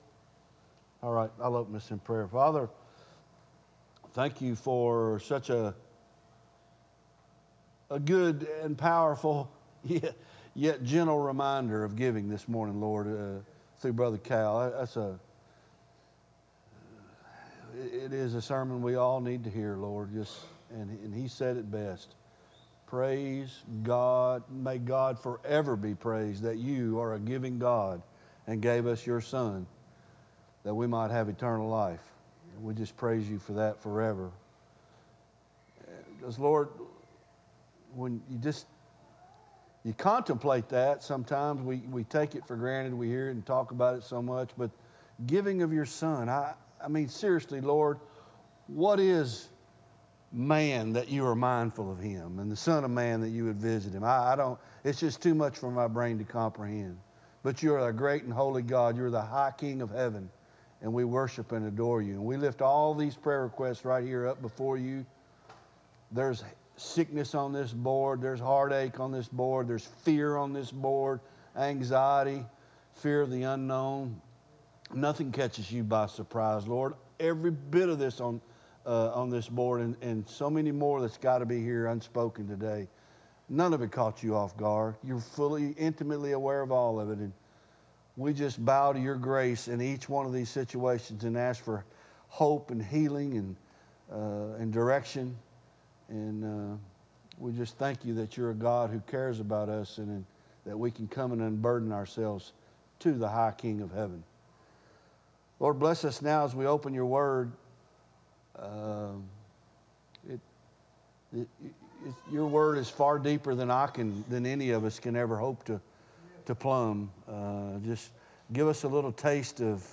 Bible Study Isaiah Ch 11